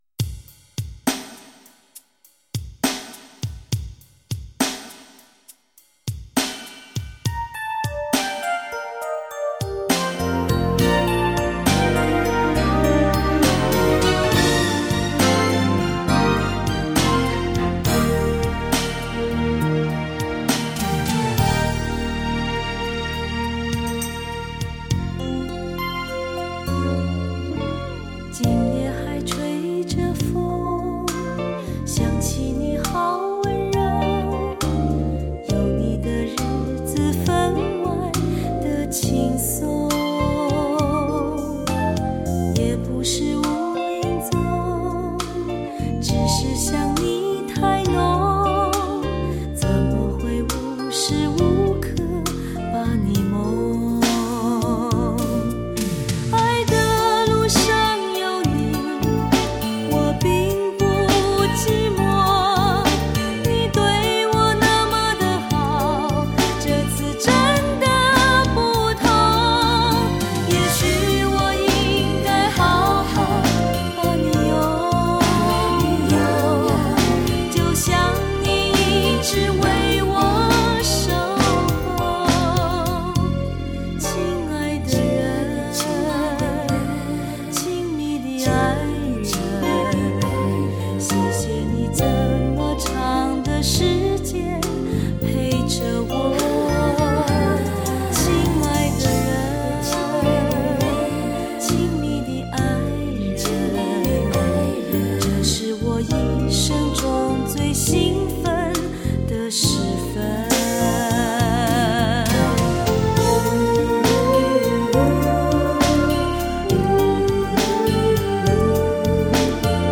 世纪歌谣永恒女唱将